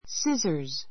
scissors 小 A2 sízə r z スィ ザ ズ 名詞 複数形 はさみ a pair of scissors a pair of scissors はさみ1丁 cut a ribbon with scissors cut a ribbon with scissors はさみでテープカットをする The scissors are not sharp.